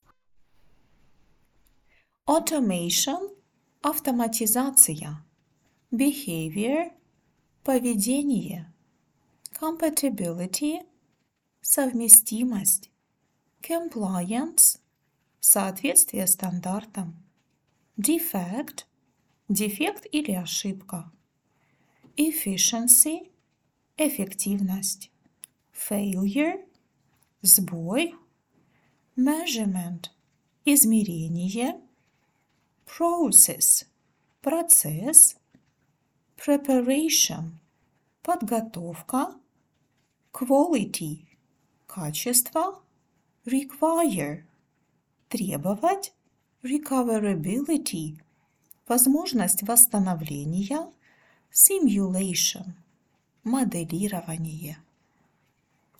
Прослушать озвучивание полезных терминов для тестировщиков на английском с русским переводом можно ниже в аудио-файле